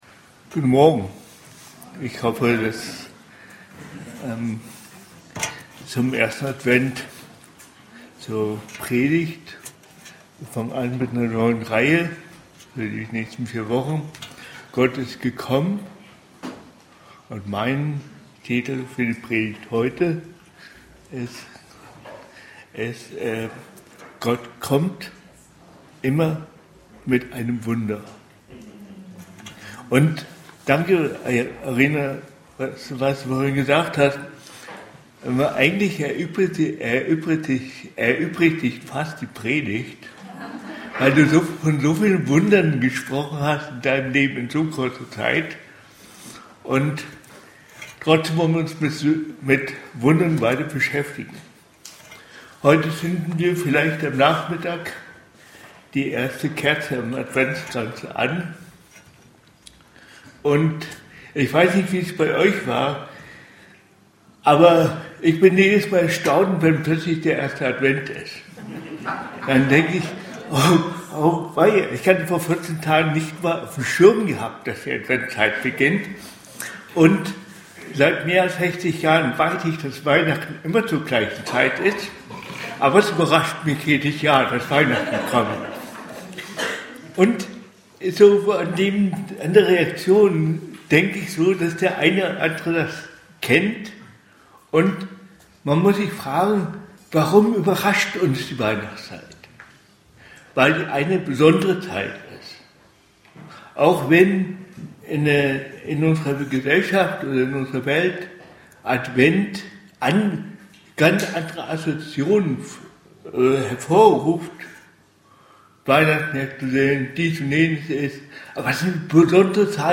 Gott kommt immer mit einem Wunder ~ BGC Predigten Gottesdienst Podcast